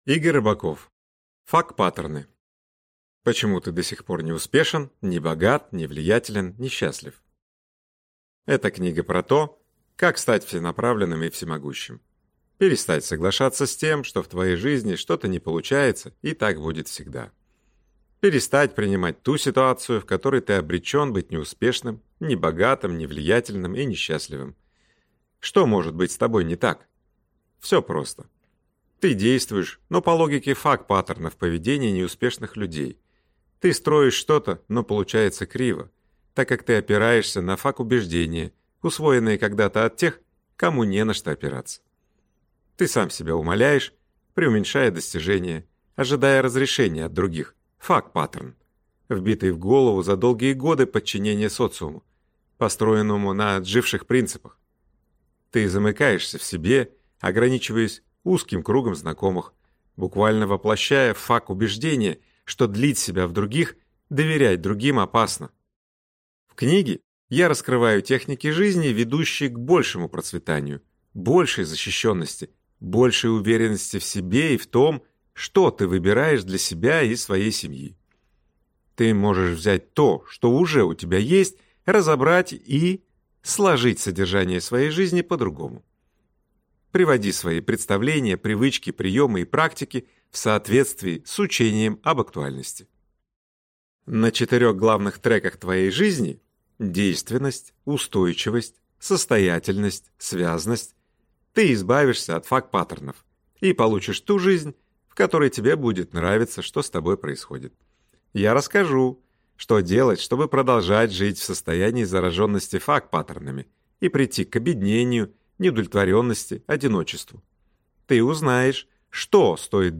Аудиокнига ФАК-паттерны. Почему ты до сих пор не успешен, не богат, не влиятелен и не счастлив | Библиотека аудиокниг